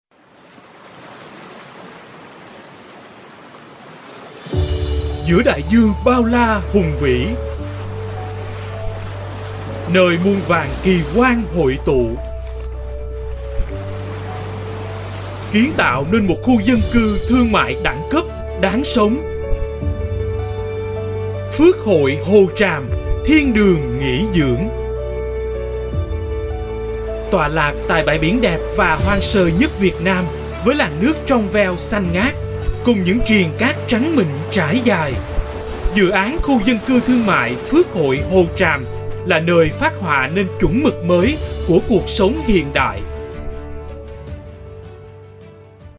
VIETNAMESE SOUTH MALE VOICES